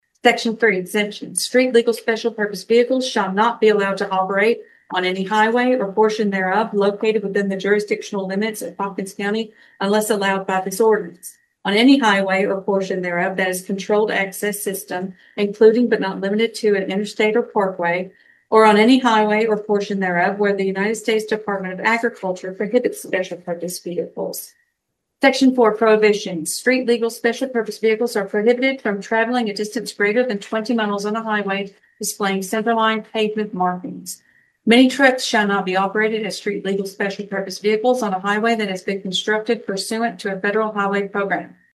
Hopkins County Fiscal Court is one step closer to allowing street-legal special purpose vehicles on certain local and state roads, following the approval of an ordinance on first reading at Tuesday morning’s meeting.